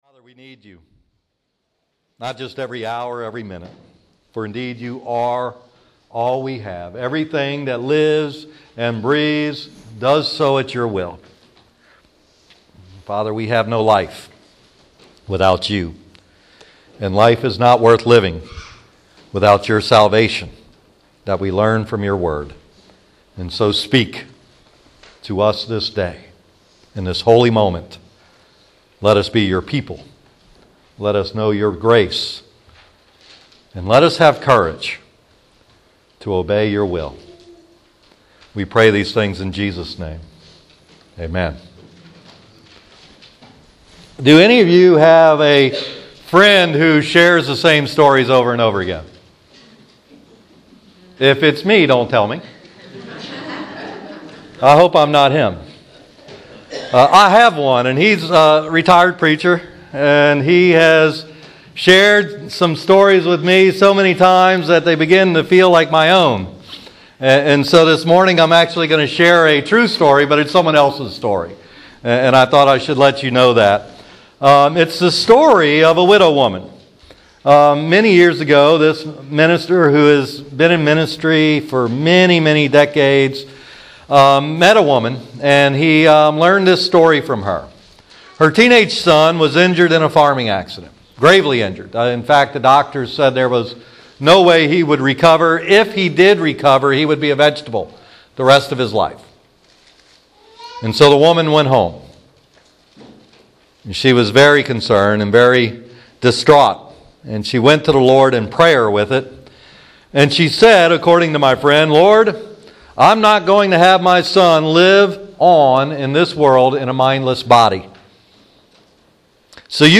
This sermon was shaped by a fishing tournament the day before.